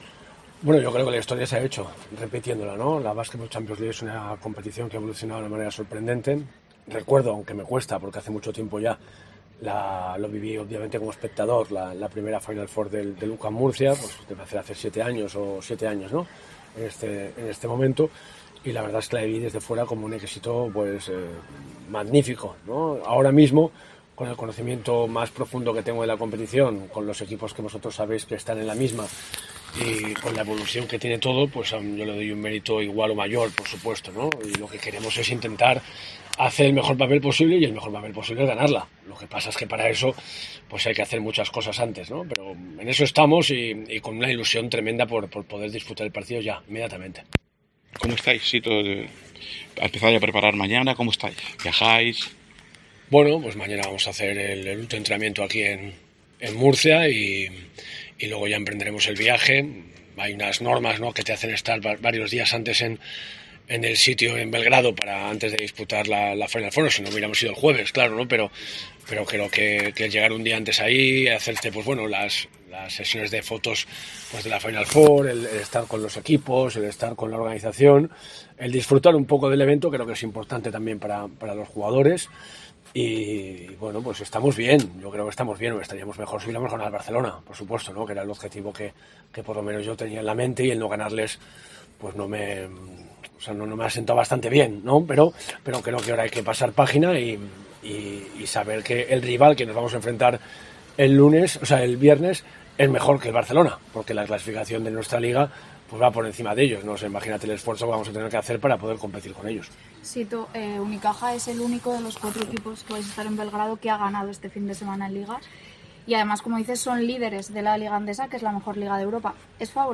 en El Café Deportivo, una charla abierta al público